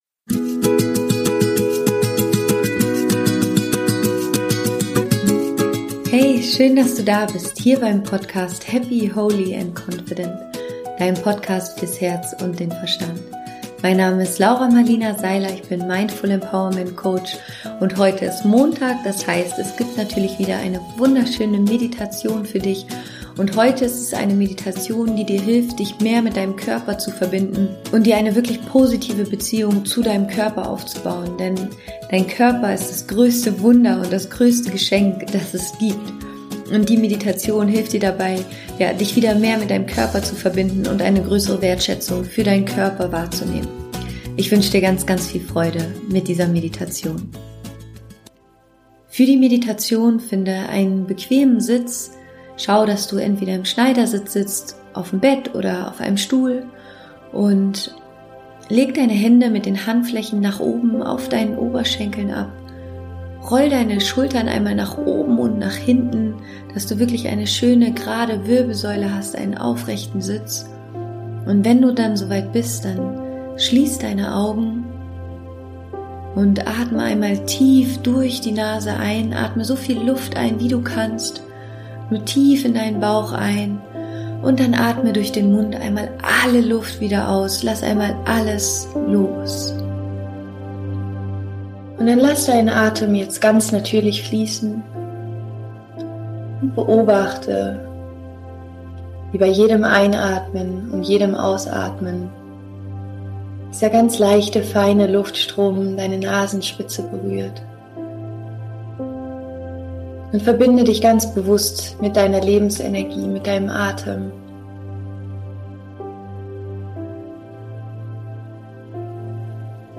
Heute wartet wieder eine neue geleitete Meditation auf dich. In dieser Meditation verbindest du dich mit deinem Körper und lernst deinen Körper zu lieben und wertzuschätzen.